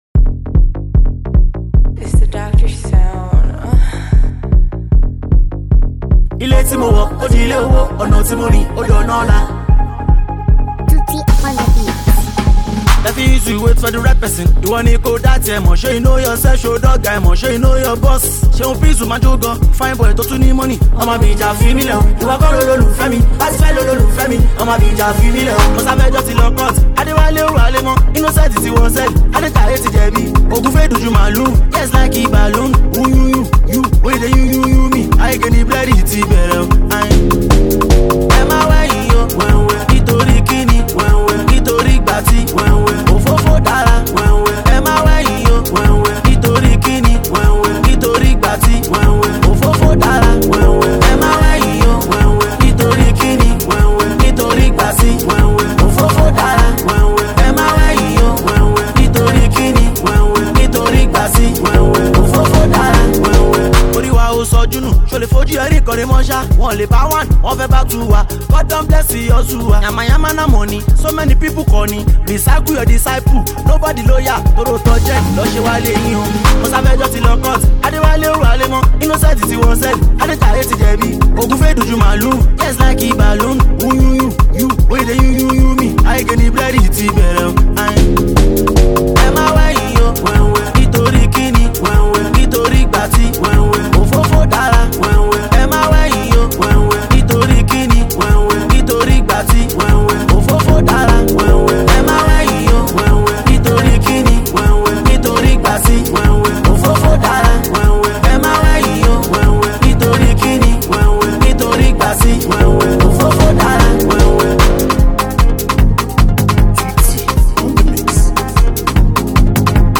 Street-hop